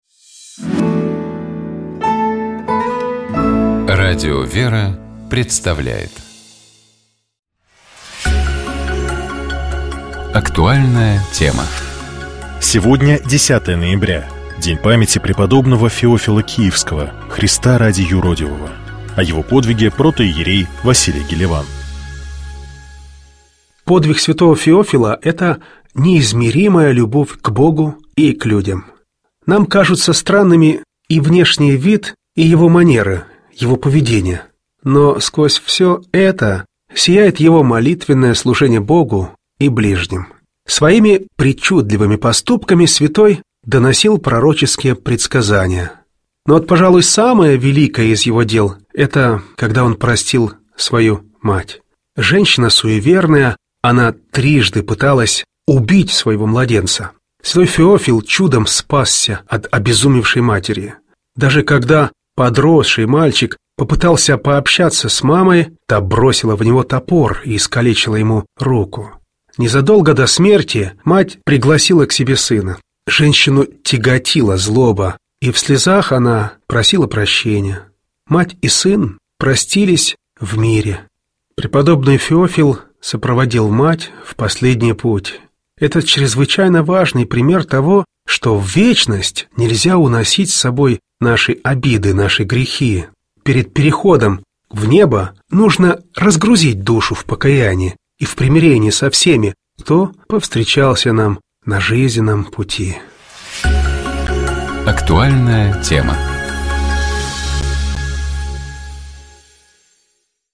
Известные актёры, режиссёры, спортсмены, писатели читают литературные миниатюры из прозы классиков и современников. Звучат произведения, связанные с утренней жизнью человека.